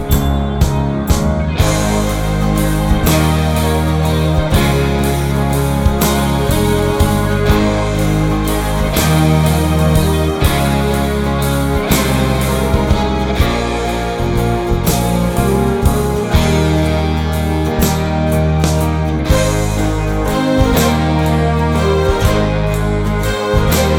no Backing Vocals Jazz / Swing 3:37 Buy £1.50